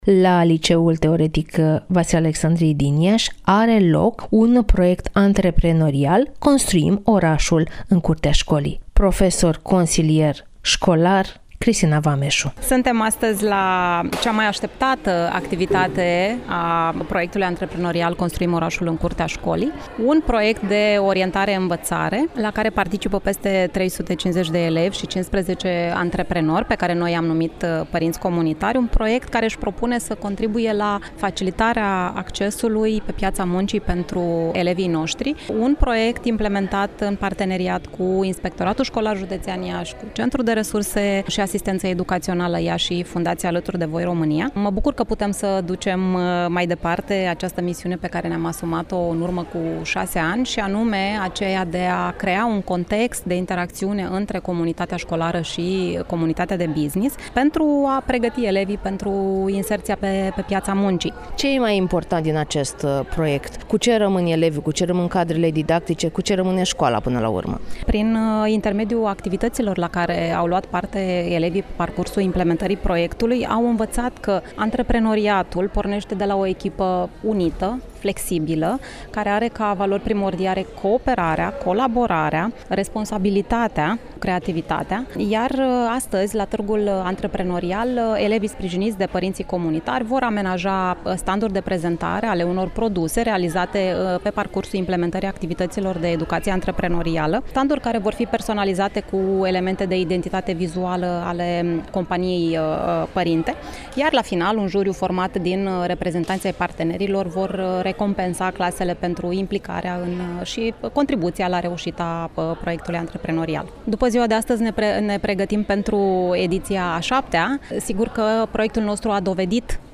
(REPORTAJ) Se încheie o nouă ediție a proiectului antreprenorial „Construim orașul în curtea școlii”